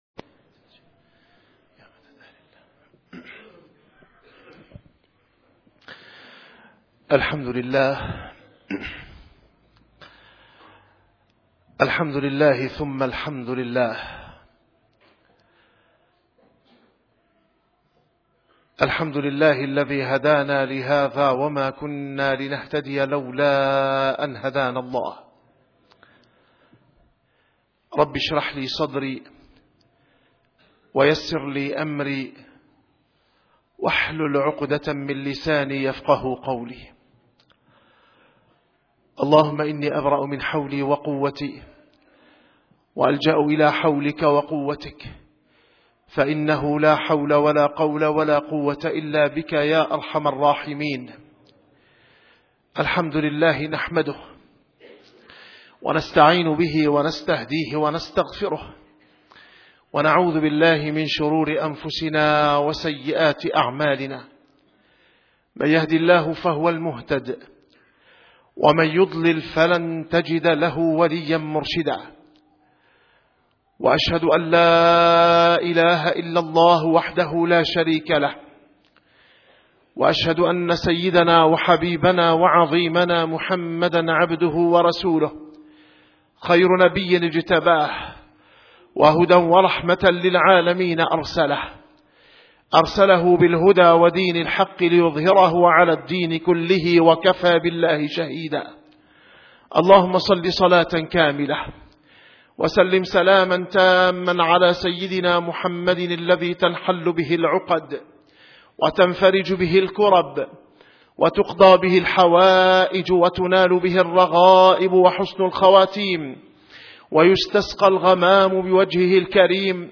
- الخطب - محمد رسول الله والذين معه